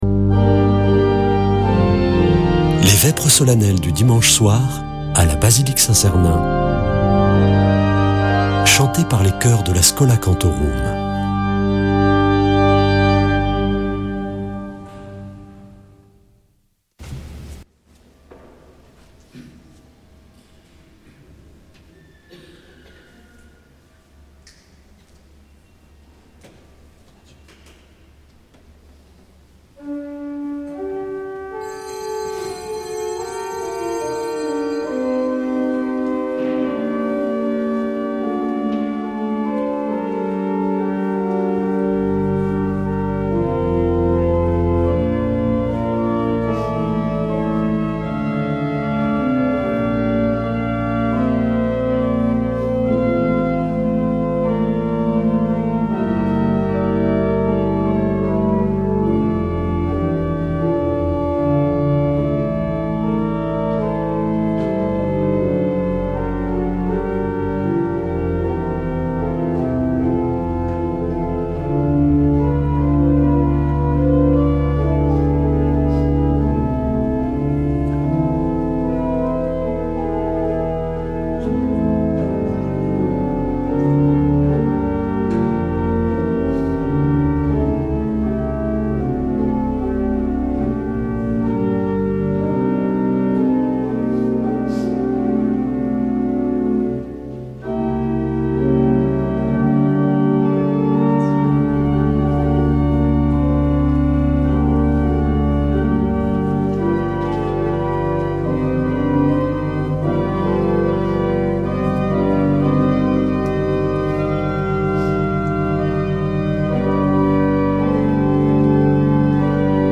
Vêpres de Saint Sernin du 29 mars